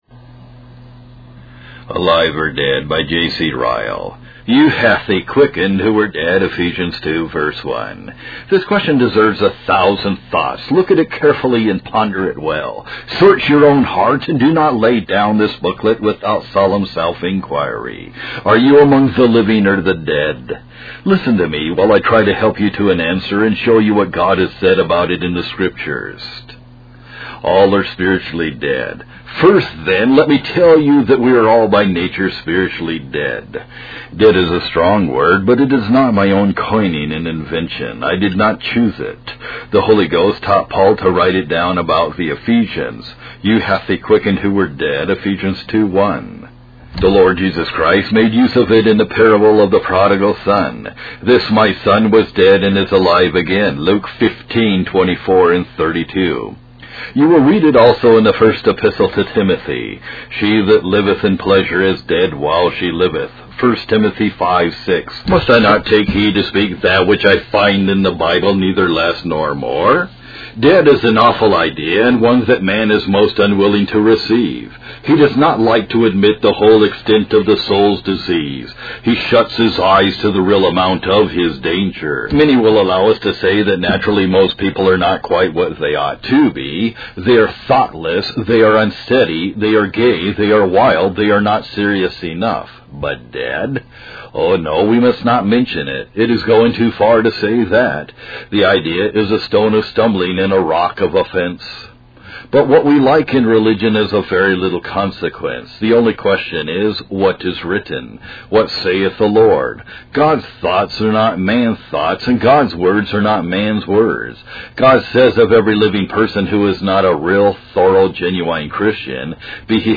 Alive or Dead (Reading) by J.C. Ryle | SermonIndex